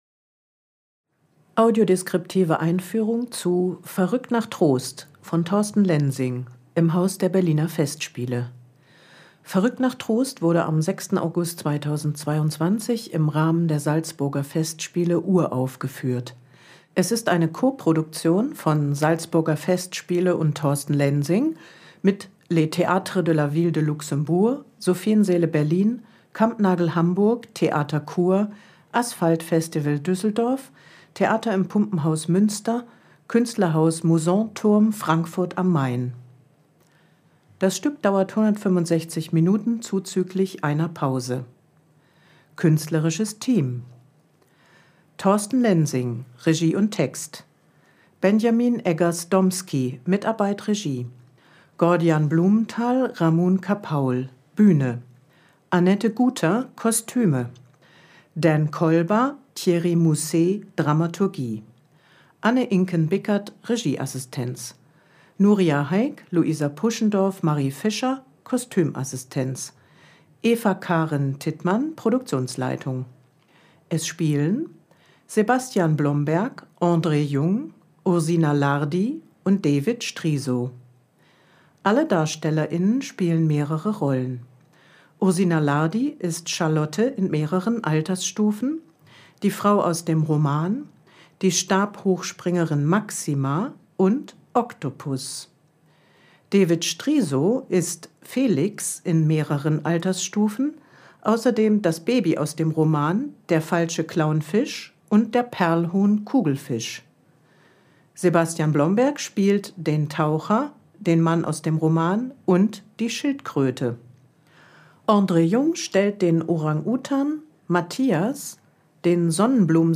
Audiodeskriptive Einführung - Verrückt nach Trost von Thorsten Lensing